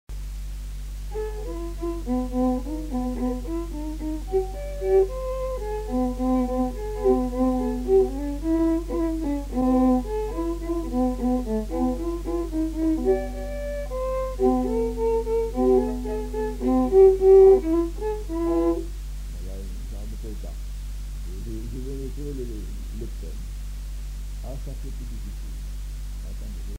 Polka
Aire culturelle : Haut-Agenais
Lieu : Cancon
Genre : morceau instrumental
Instrument de musique : violon
Danse : rondeau